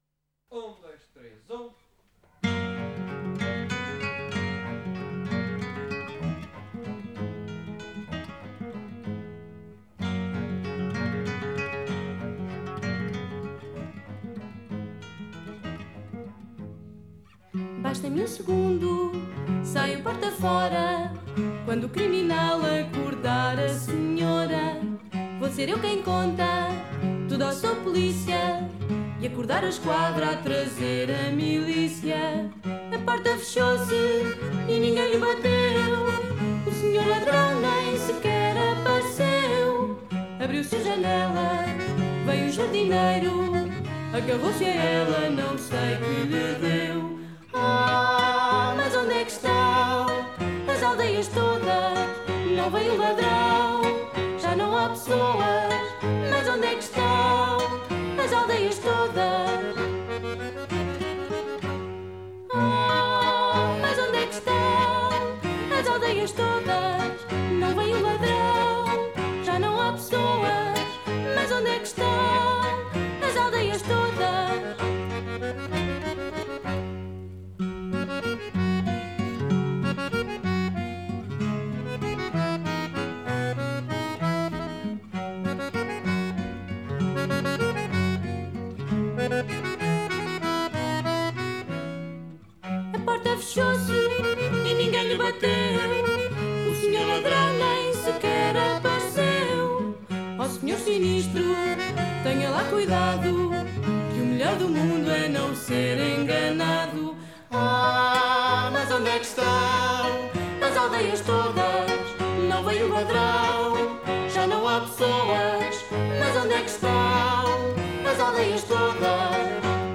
Genre: Fado, Ballad